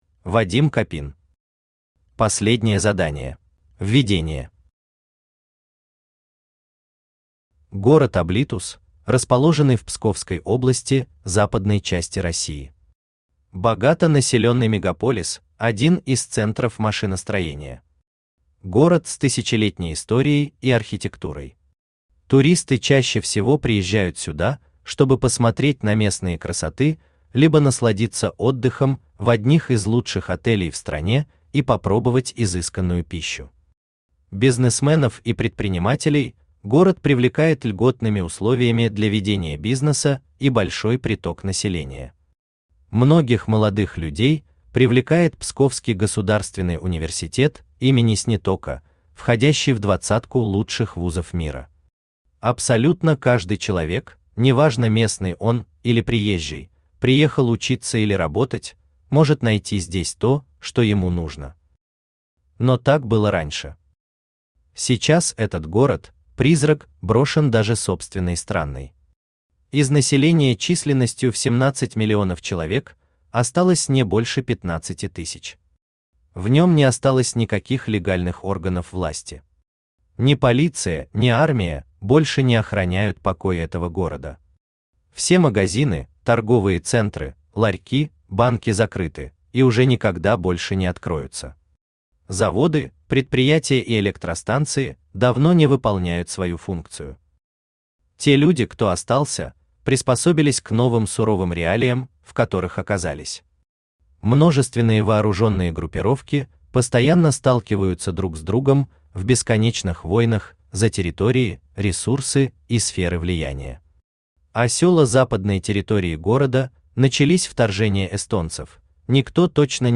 Аудиокнига Последнее задание | Библиотека аудиокниг
Aудиокнига Последнее задание Автор Вадим Вячеславович Копин Читает аудиокнигу Авточтец ЛитРес.